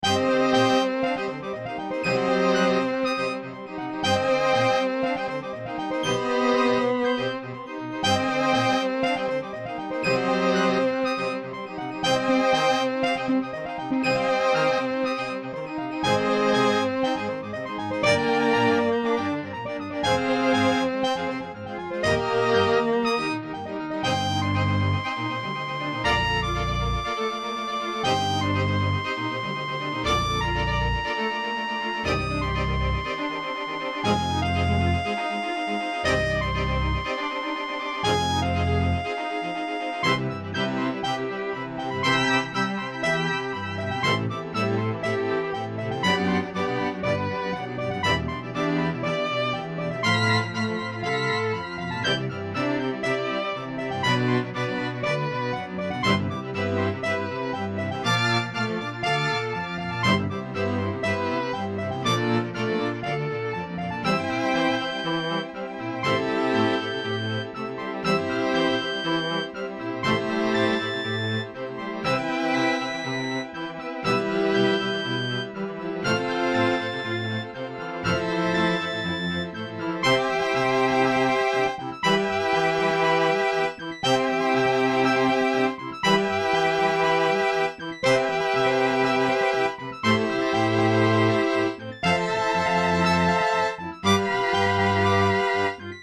ミュート トランペット、バイオリン、ビオラ、チェロ